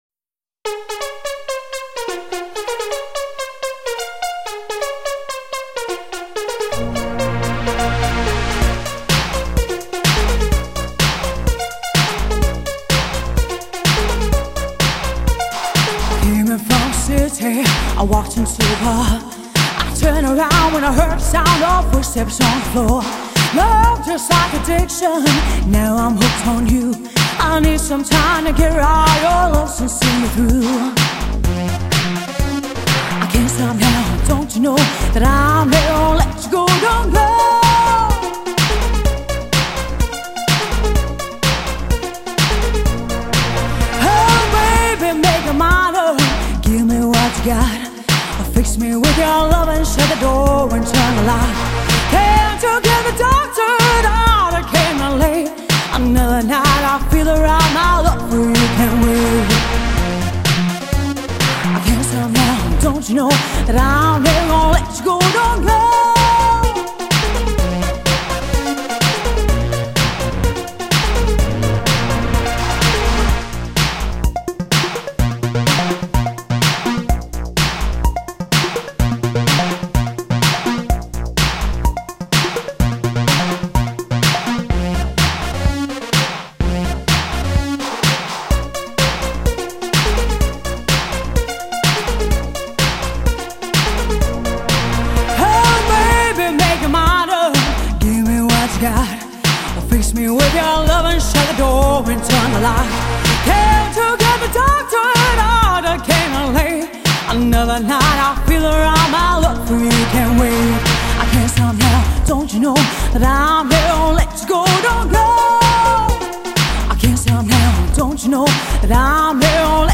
pop rock wedding and cabaret singer
a highly engaging personality and a rich and powerful voice.